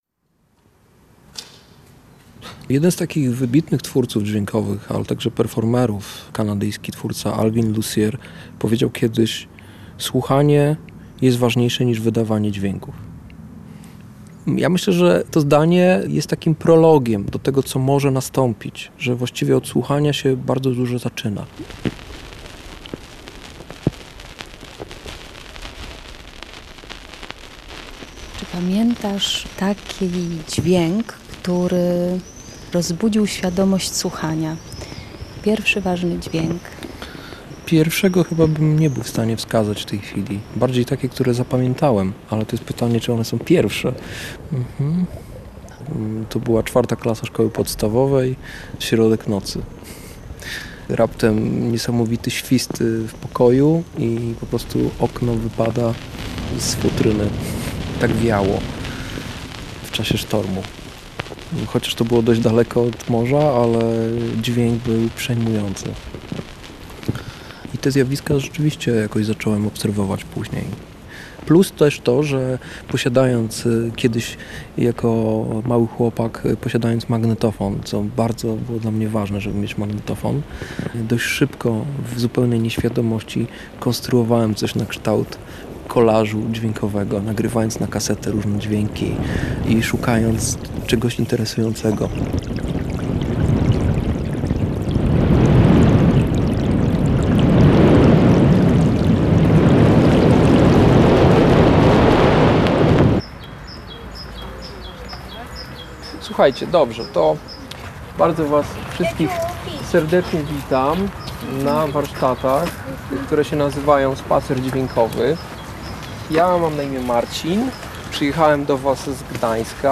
Fot. archiwum Otwórz uszy Tagi: reportaż